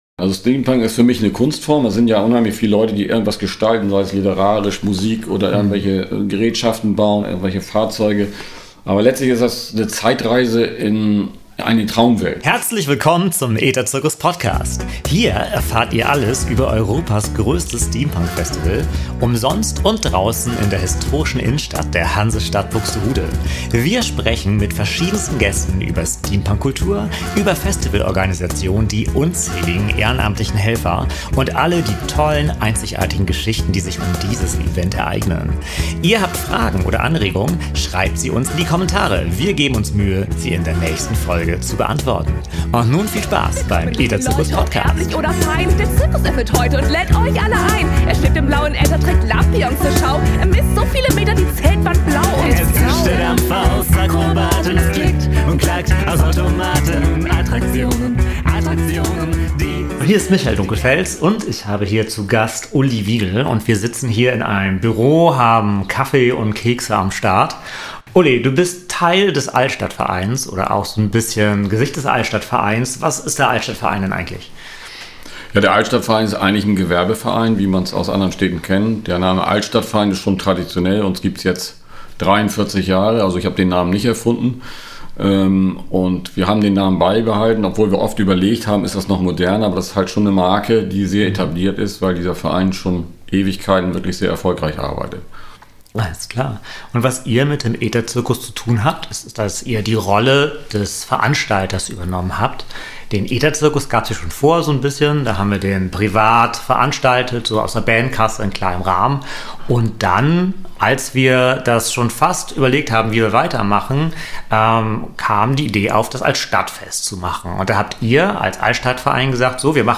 Ein Gespräch über die Umsetzung von Europas größtem Steampunk-Festival